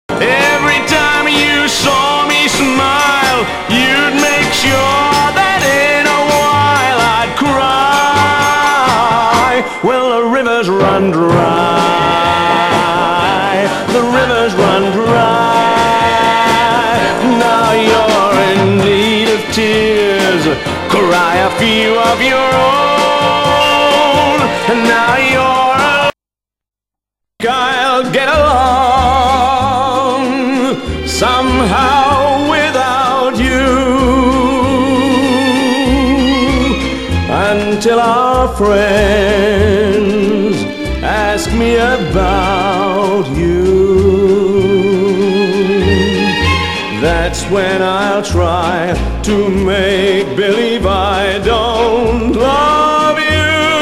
(税込￥3080)   POP